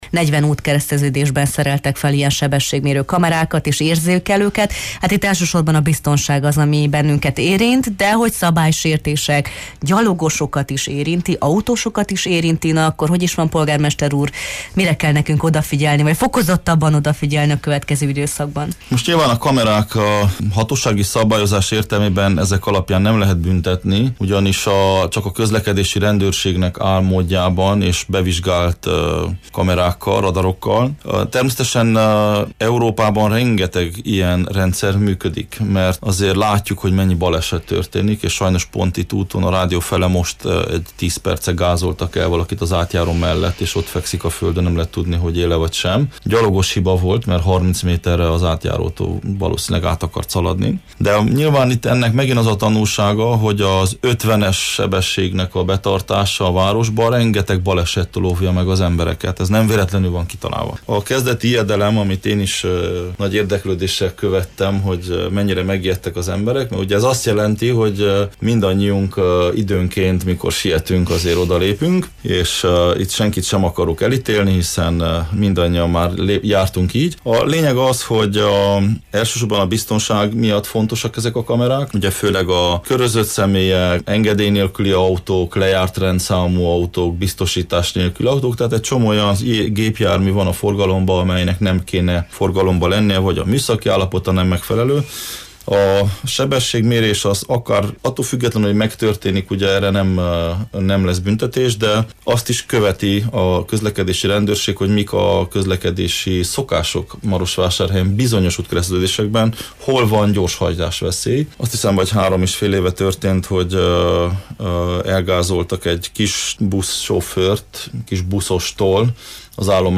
A Jó reggelt, Erdély! című műsorban Soós Zoltán városvezetővel beszélgettünk a várost érintő legfontosabb kérdésekről.